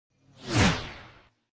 rocket.mp3